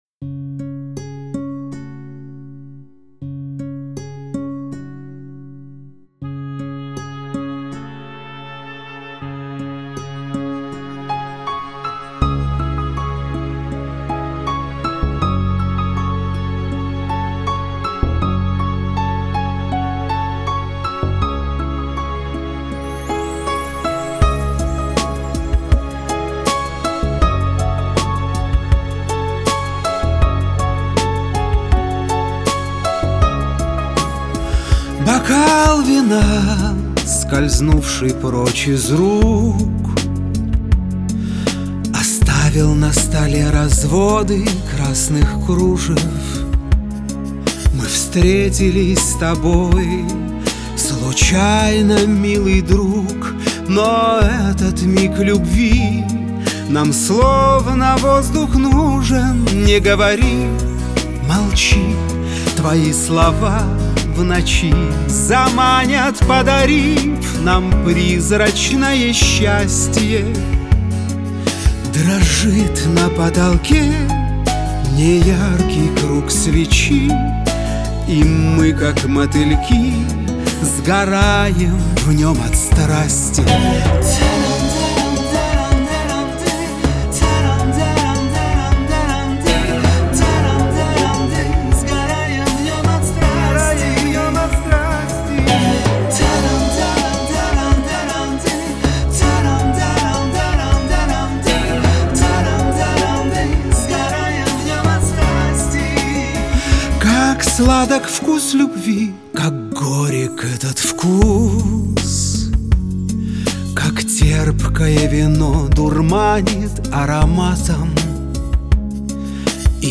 Клавишные, синтезаторы, перкуссия :